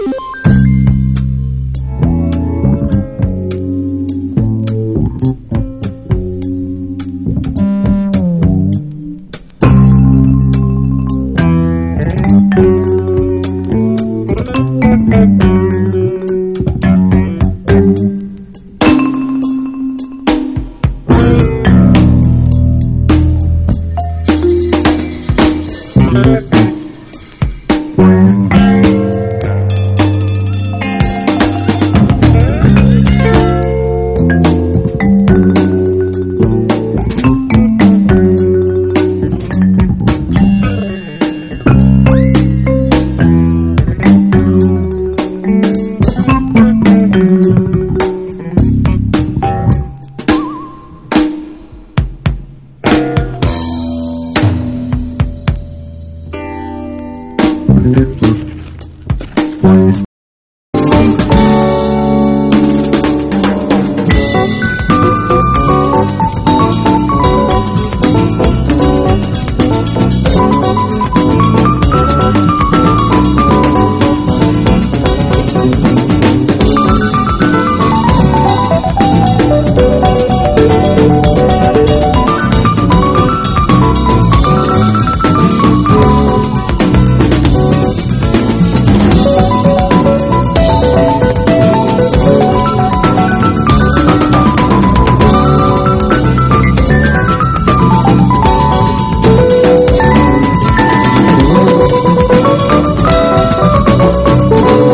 ROCK / 70'S / MODERN POP / NICHE POP (UK)
ナイス・アレンジのニッチ・ポップ/モダン・ポップ/A.O.R.！